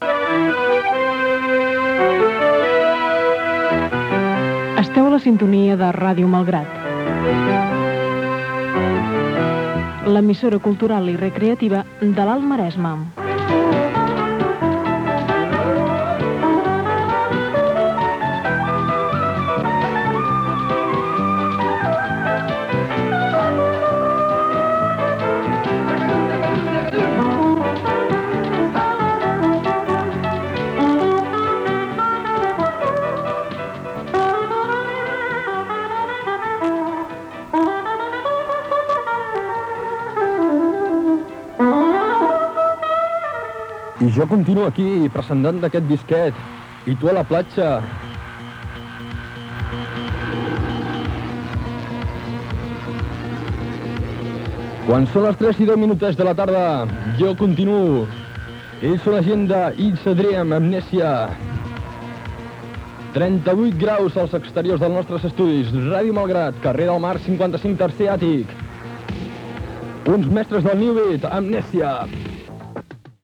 b04cf7825b9e1ca73a362cc93b55547c125adb19.mp3 Títol Ràdio Malgrat Emissora Ràdio Malgrat Titularitat Pública municipal Descripció Indicatiu i presentació d'una tema musical.